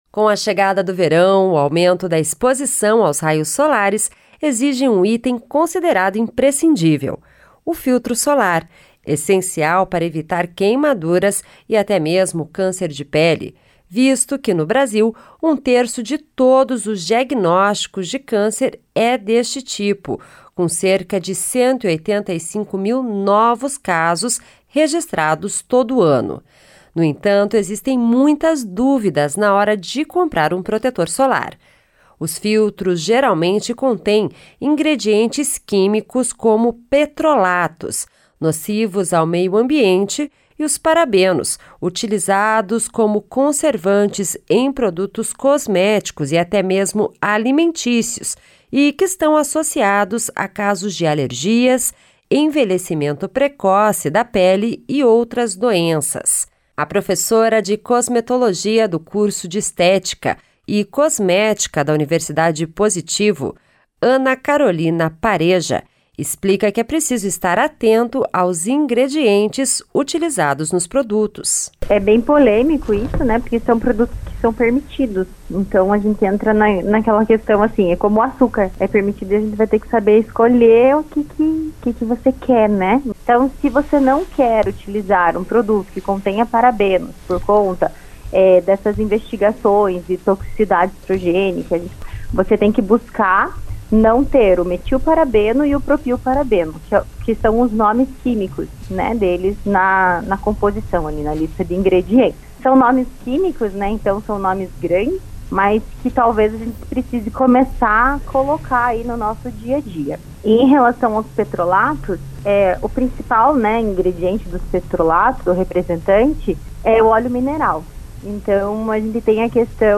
Especialista explica que é possível se proteger do sol de forma adequada, e escolher um filtro que tenha em sua composição substâncias livres de químicas nocivas à saúde e ao meio ambiente.